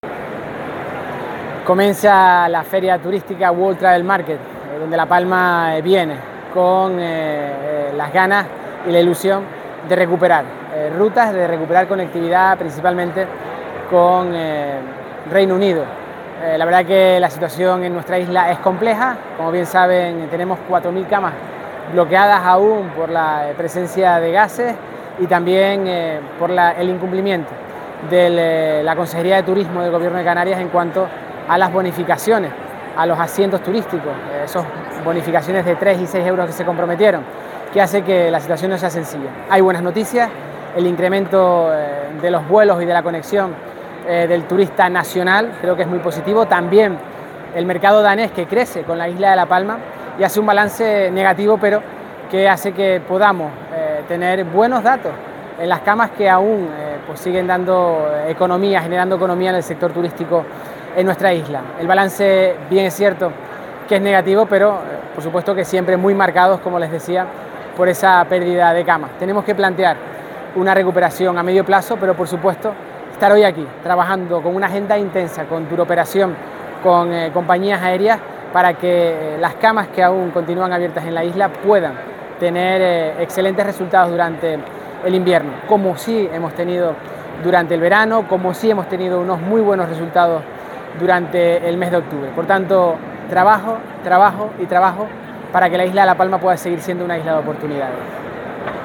Declaraciones audio Mariano Zapata WTM.mp3